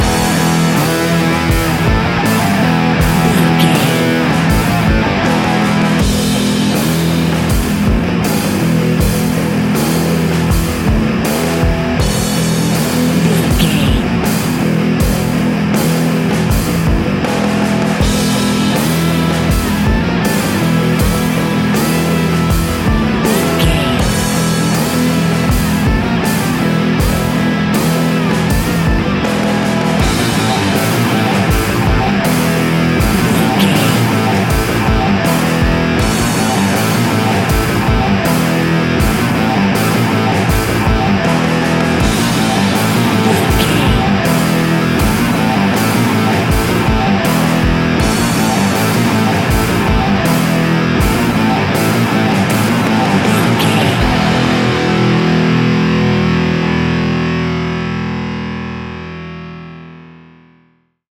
Ionian/Major
hard rock
heavy metal
distortion
instrumentals